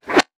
weapon_bullet_flyby_21.wav